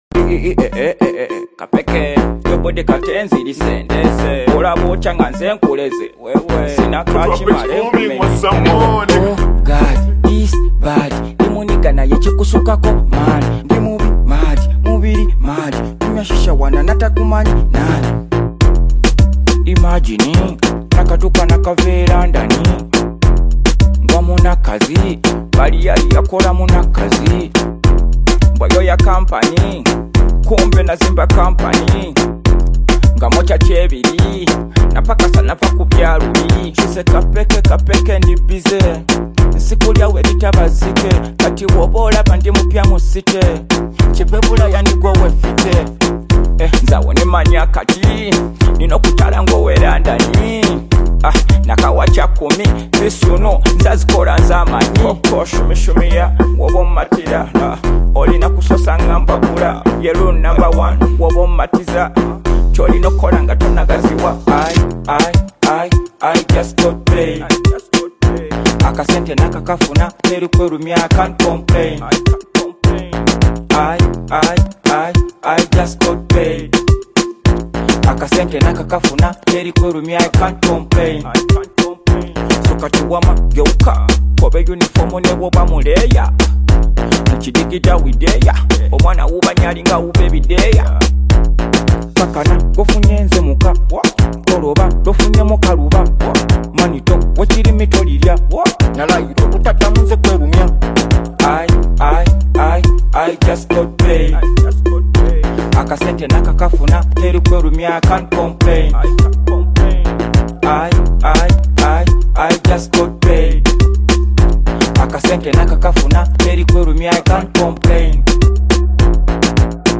blending catchy melodies with meaningful songwriting.
Genre: Afro-Beats